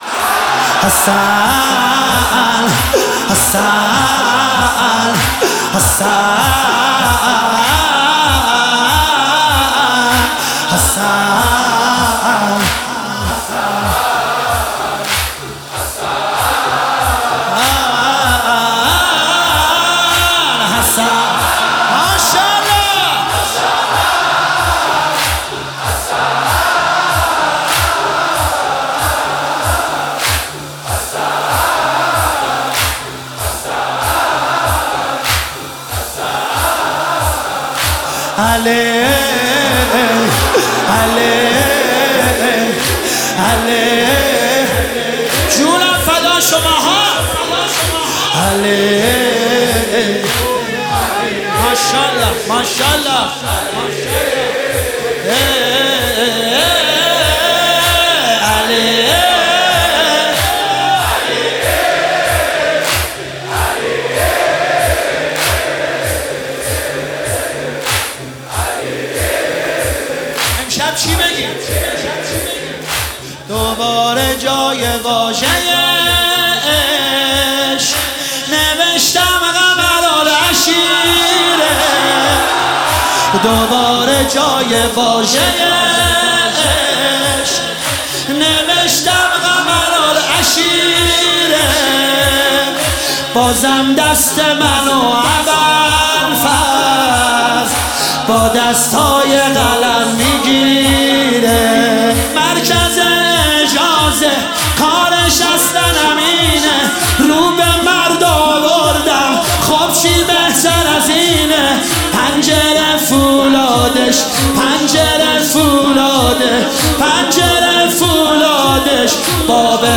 مداحی واحد